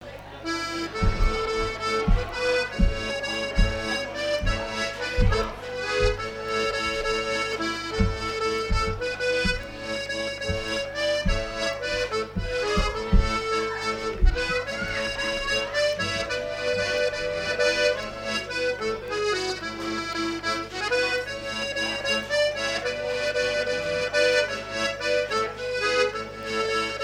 Valse par des accordéonistes de la fête
danse : valse
Fête de l'accordéon
Pièce musicale inédite